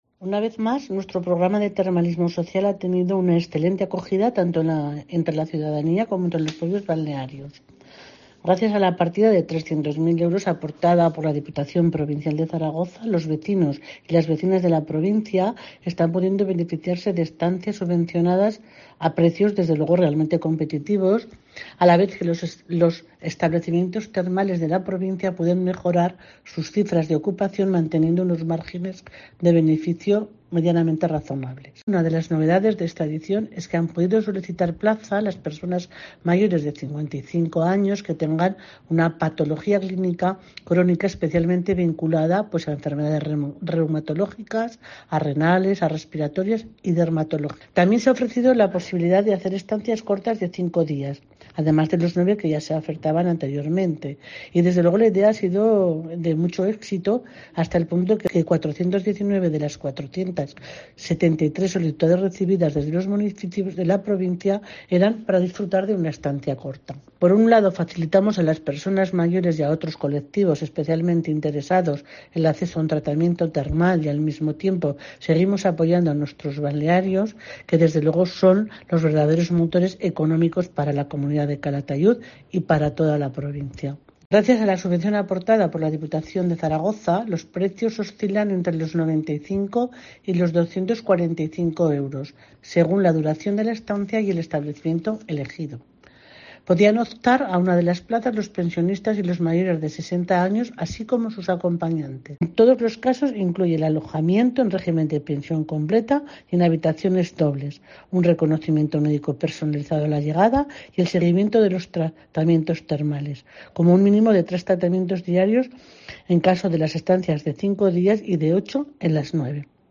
La delegada de Bienestar Social de la DPZ, Mercedes Trébol, sobre el programa de Termalismo 2023.